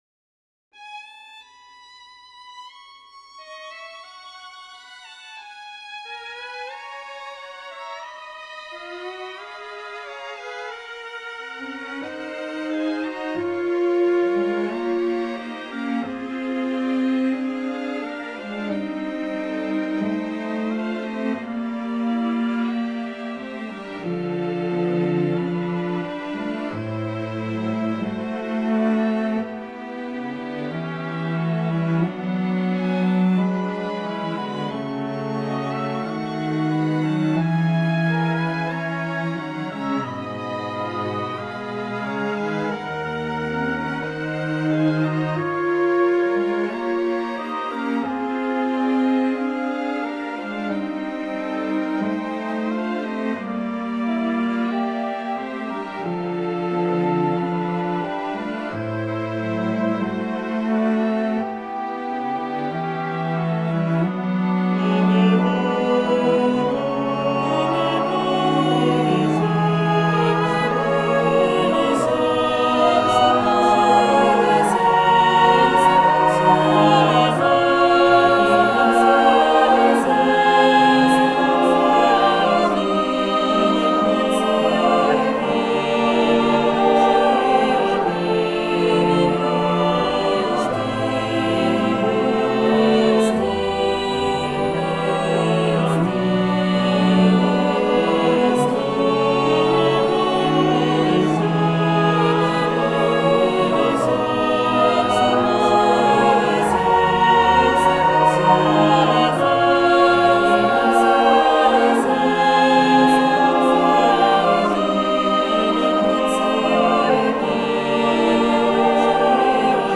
Pantonal Perpetual Canon No. 3 in Hypodiatessaron for Choir and Orchestra.